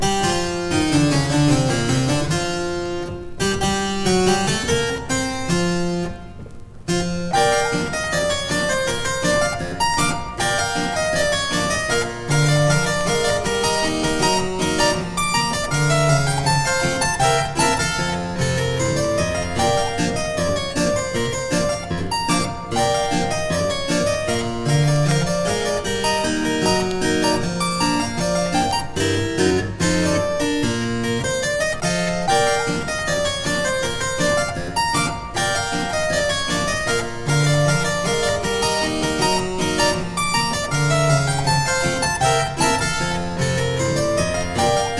針を落とせば、遊園地にいるかのようなイメージも広がるハッピーな音楽集。
Jazz, Ragtime　USA　12inchレコード　33rpm　Stereo